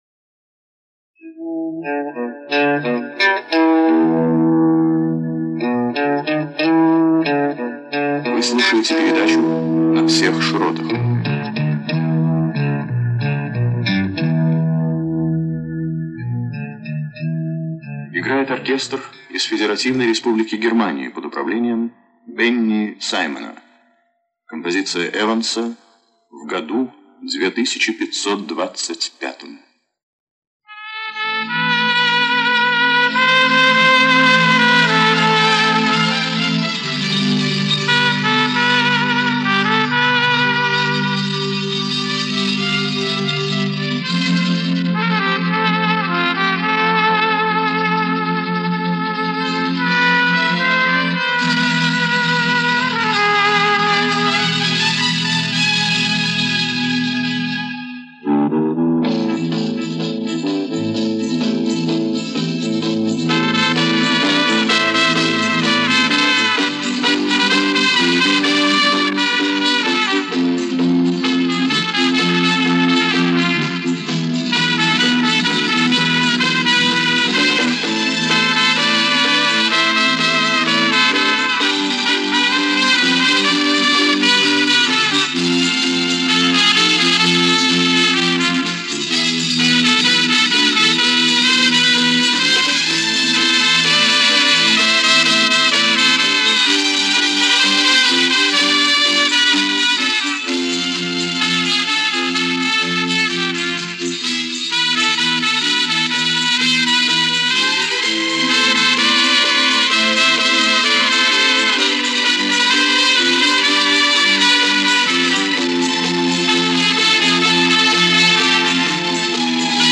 запись с эфира передачи